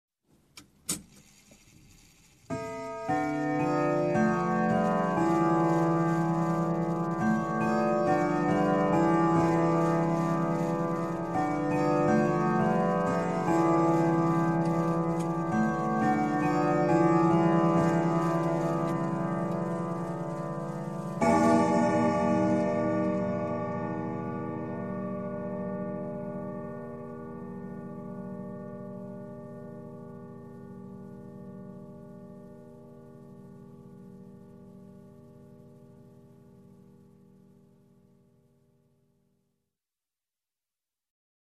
Звук времени, бой в 12 часов
Часы, бой часов
Часы: бой в 12 часов